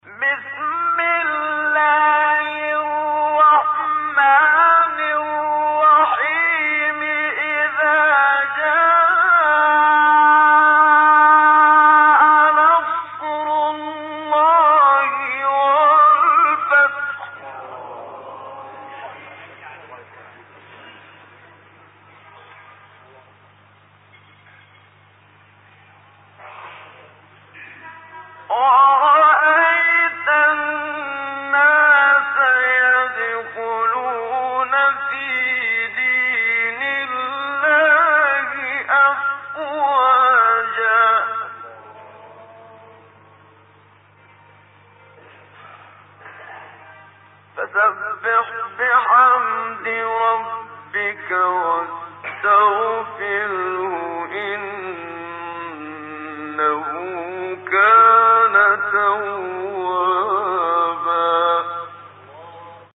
تلاوت مجلسی استاد محمد صدیق المنشاوی - آیات یک تا سه سوره مبارکه نصر